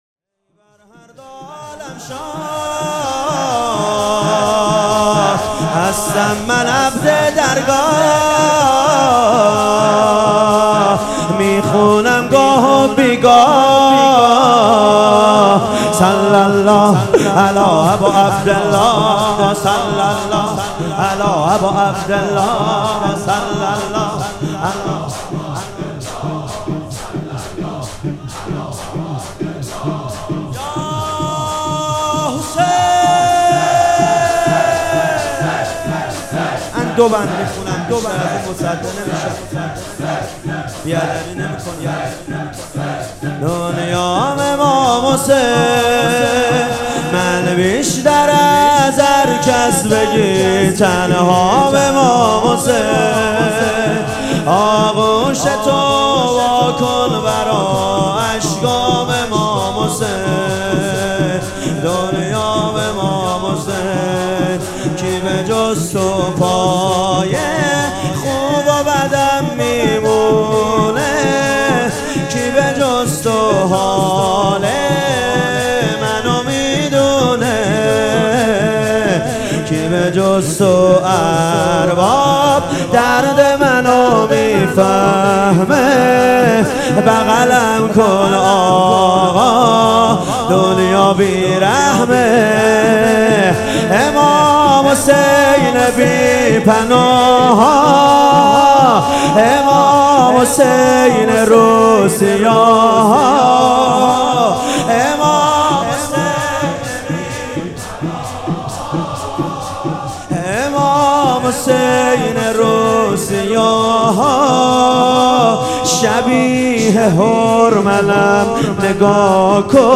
سبک اثــر شور مداح